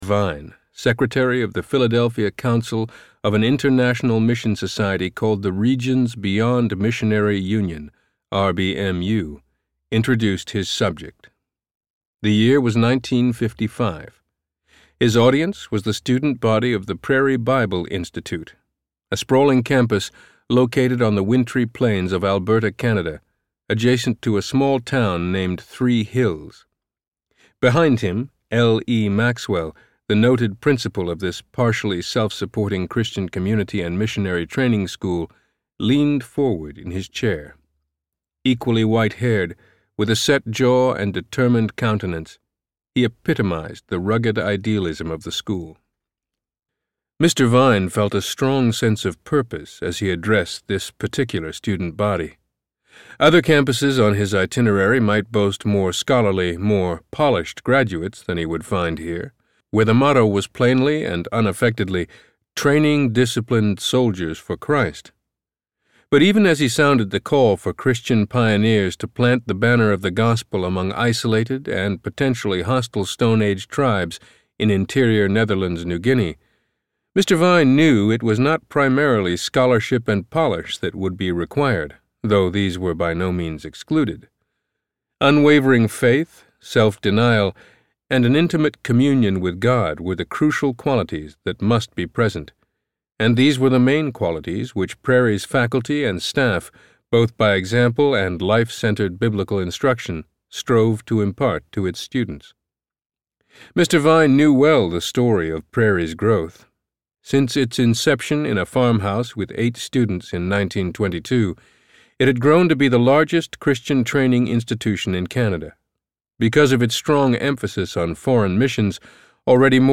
Peace Child Audiobook
6 Hrs. – Unabridged